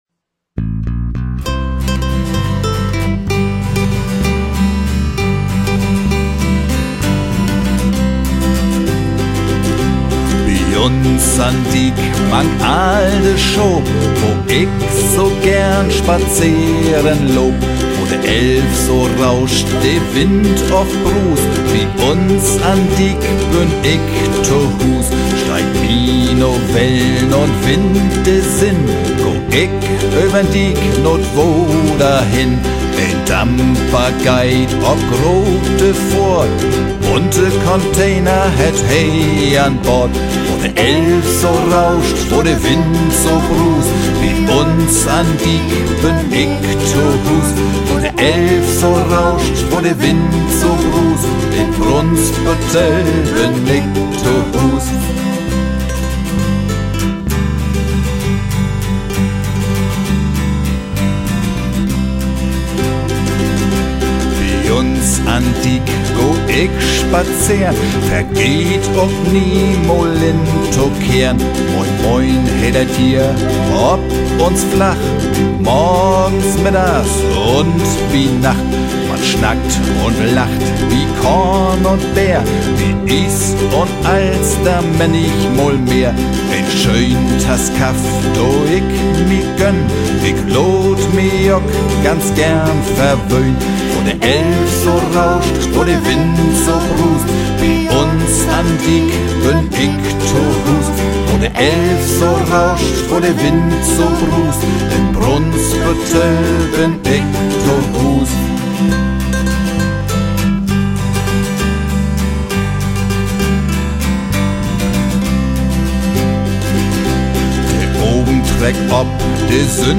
aufgenommen im Oktober 2019 im Tonstudio